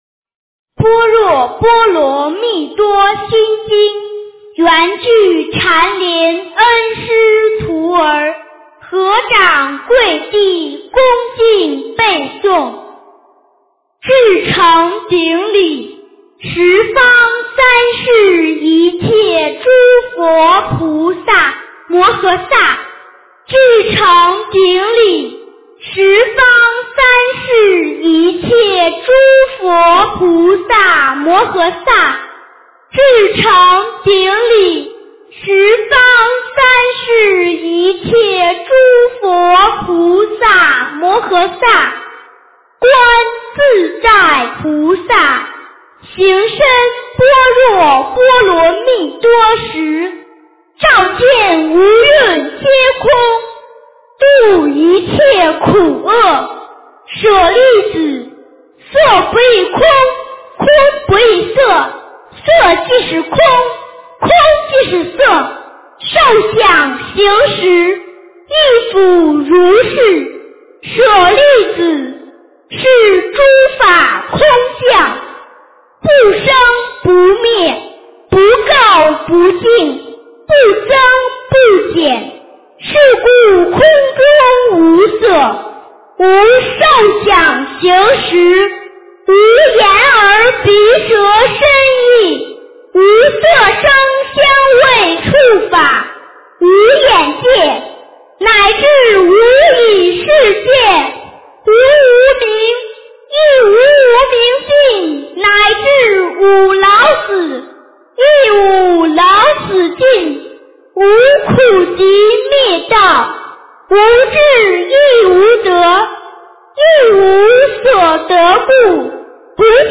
诵经
佛音 诵经 佛教音乐 返回列表 上一篇： 大悲咒 下一篇： 心经-恭诵 相关文章 和平的通道--佛教音乐(世界禅风篇